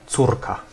Ääntäminen
France: IPA: [fij]